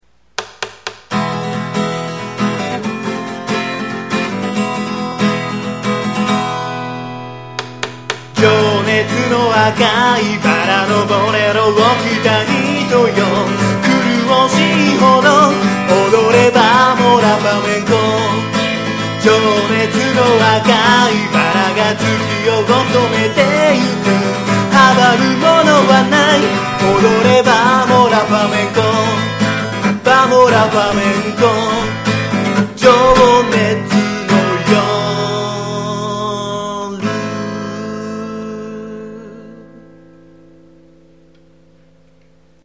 録音環境が整ってないので音割れしまくるのは仕様！！